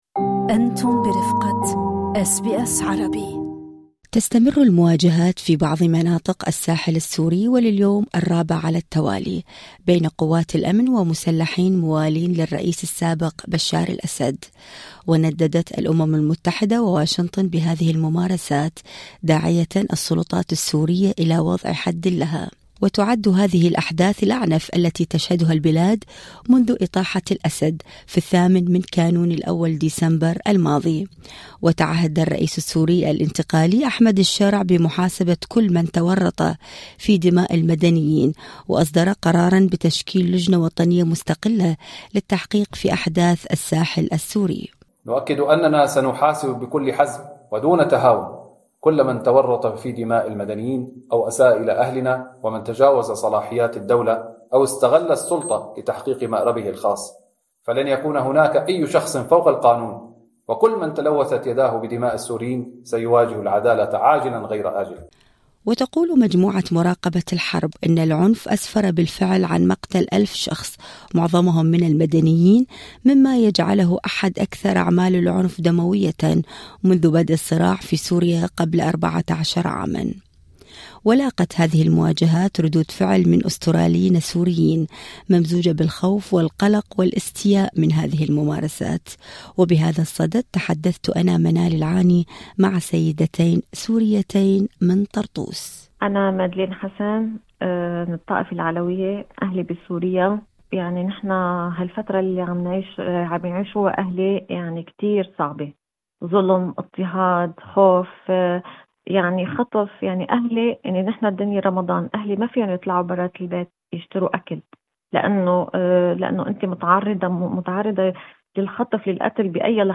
"الأمان تزعزع والوضع خطير": سيدتان سوريتان يطالبن الحكومة بإنقاذ الأهل في طرطوس